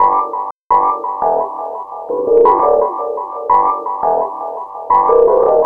06_ChickendonCode_170_Emin.wav